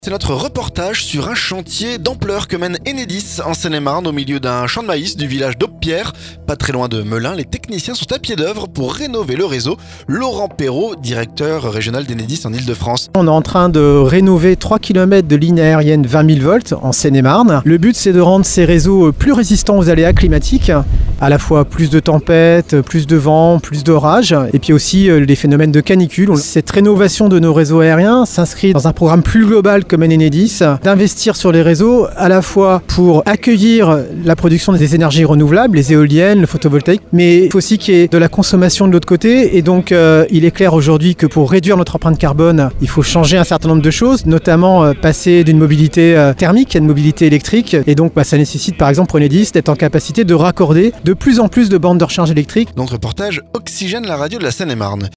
ENEDIS - Reportage sur un chantier de rénovation à Aubepierre